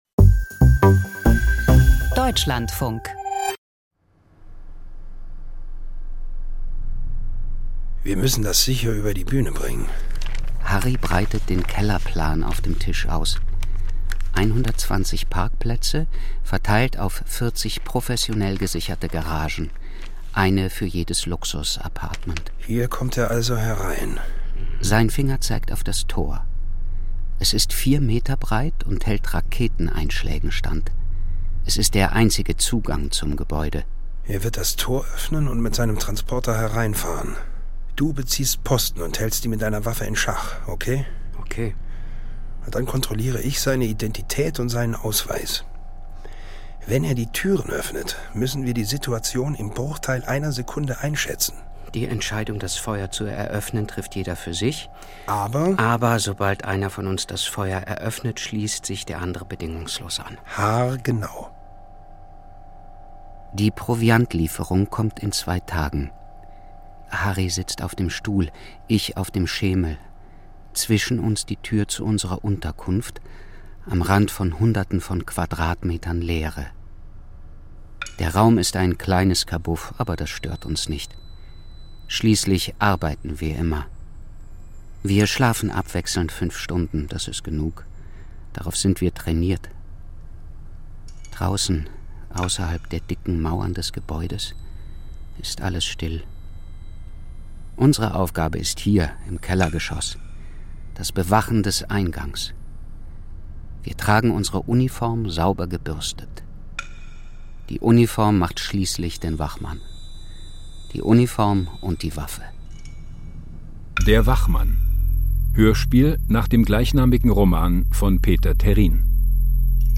„Der Wachmann“ - Hörspiel-Thriller: Wachpersonal im Ausnahmezustand ~ Kriminalhörspiel Podcast